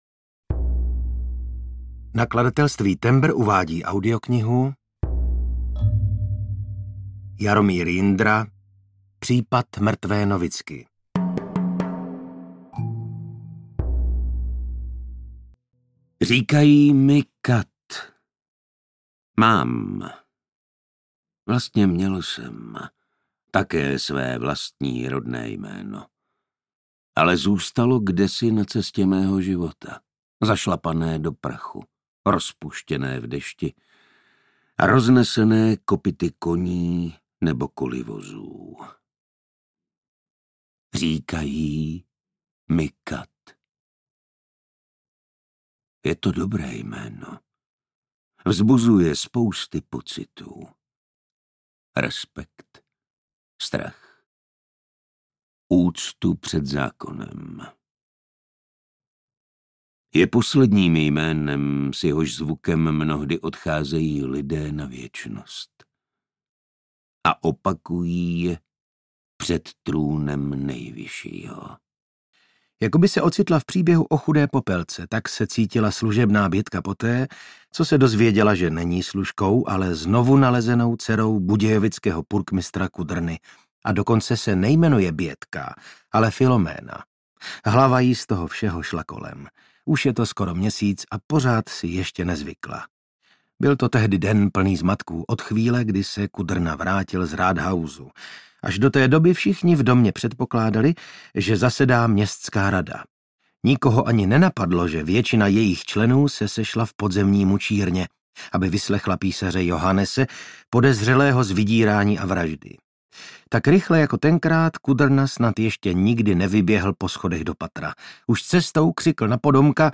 Případ mrtvé novicky audiokniha
Ukázka z knihy
• InterpretVasil Fridrich, Martin Stránský